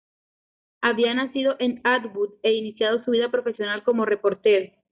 re‧por‧te‧ro
/repoɾˈteɾo/